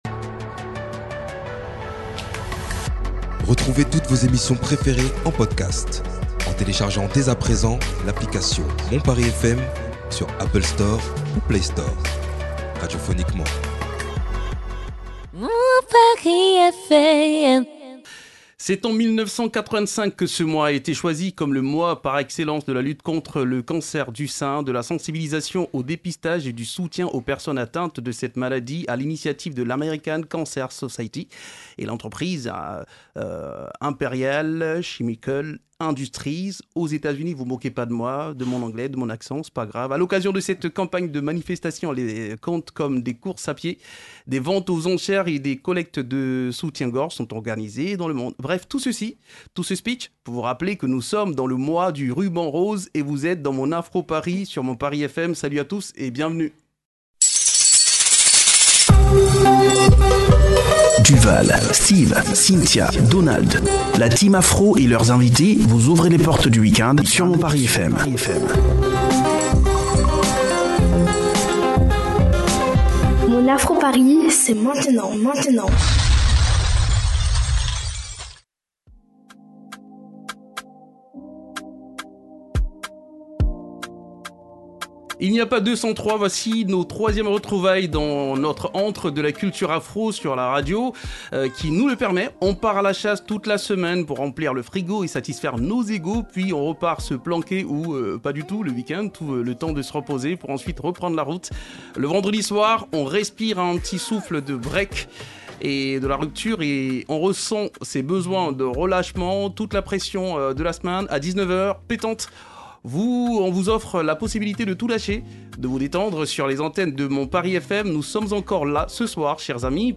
Le talkshow africain vous fait voyager dans 2 univers poétiques et plus ou moins opposés dans le rythme, de la musique urbaine